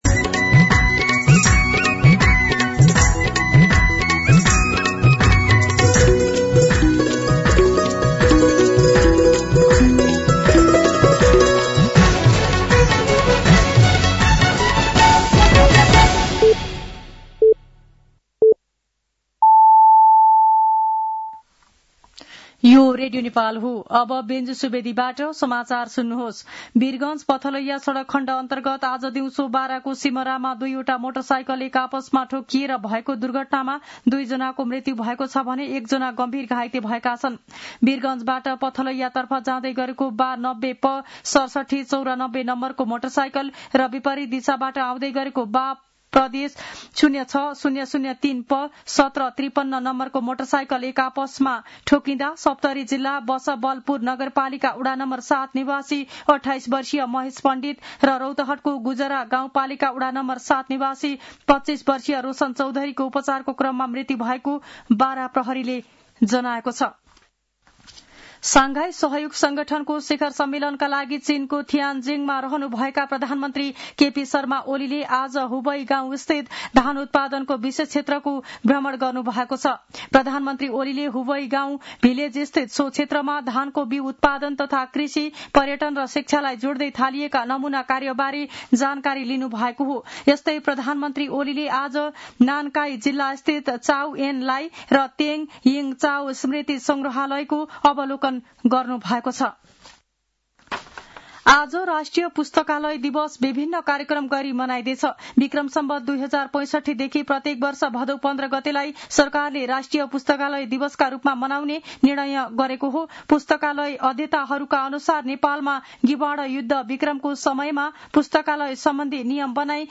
साँझ ५ बजेको नेपाली समाचार : १५ भदौ , २०८२
5.-pm-nepali-news-1-10.mp3